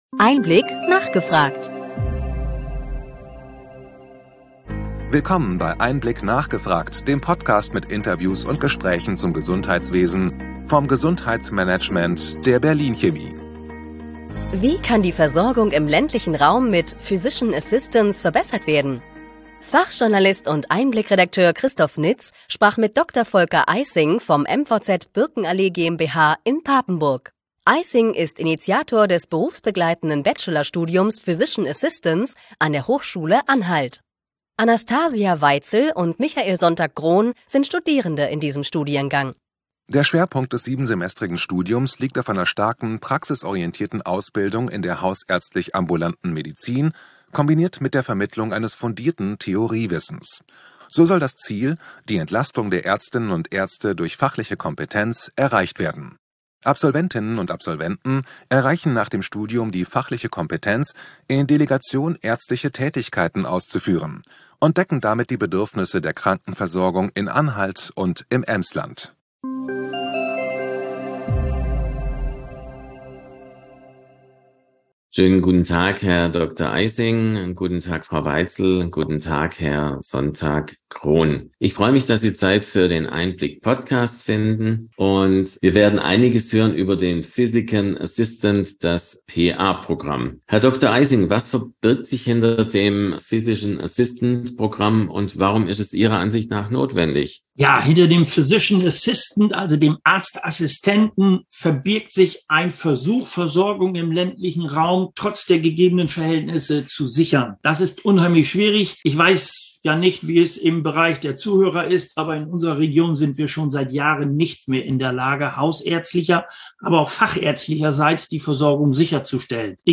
EinBlick – nachgefragt Podcast mit Interviews und Diskussionsrunden mit Expert:innen des Gesundheitswesens Wie kann die Versorgung im ländlichen Raum mit Physician Assistants verbessert werden?